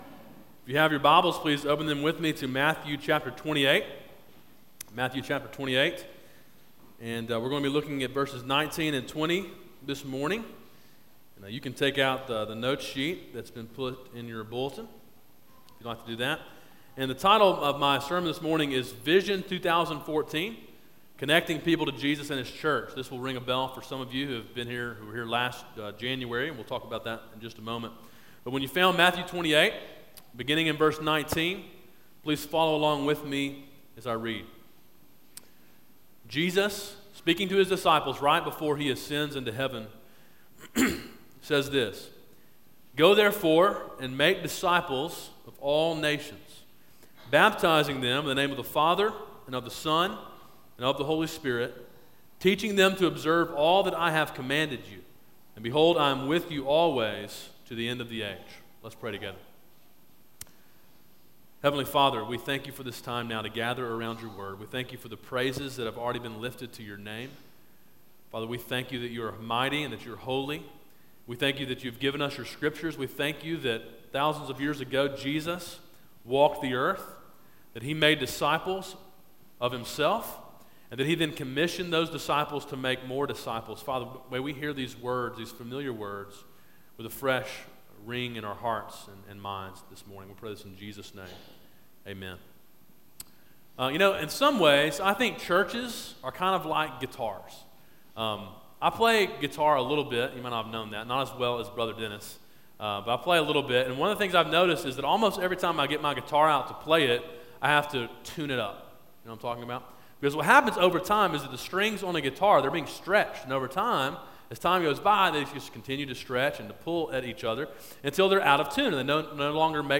A sermon in Calvary's 2014 Vision series.
sermon-1-05-14.m4a